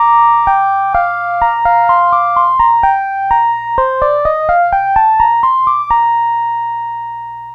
Track 10 - Synth 02.wav